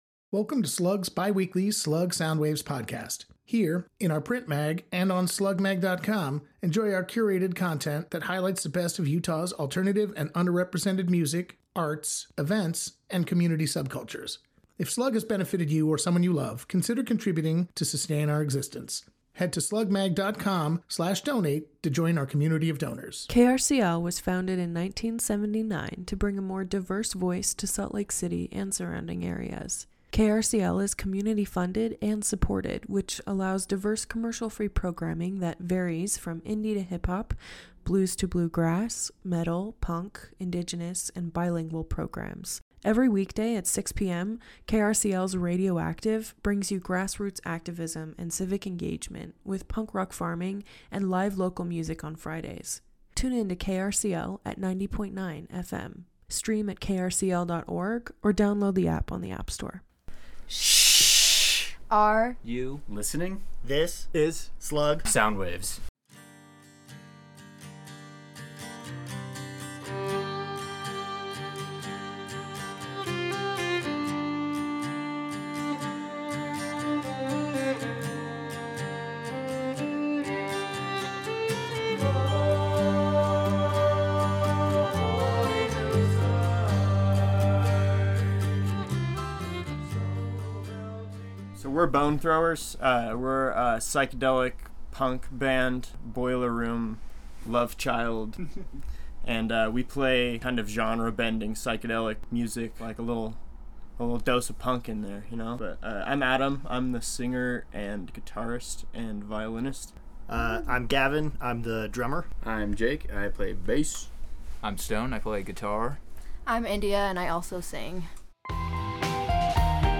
garage rock, deviant psychedelia and gristly surf